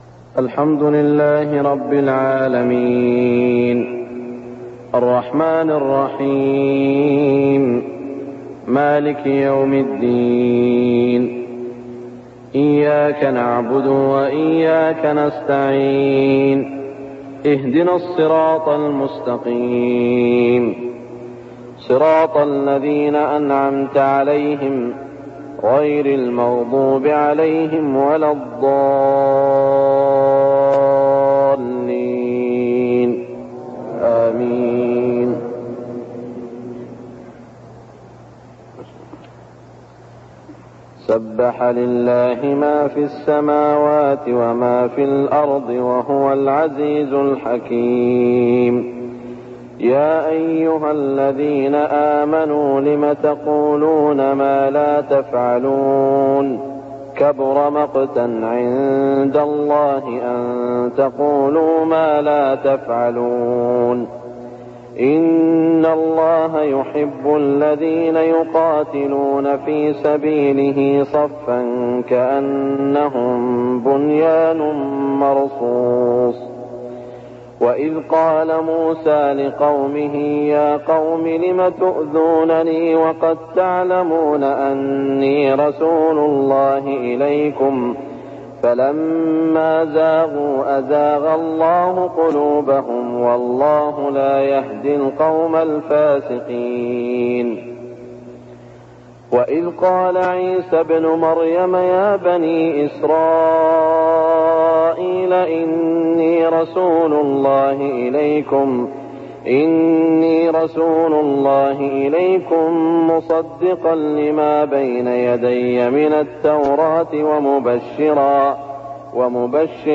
صلاة الفجر 1413هـ سورة الصف > 1413 🕋 > الفروض - تلاوات الحرمين